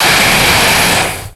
Cri de Kyurem dans Pokémon X et Y.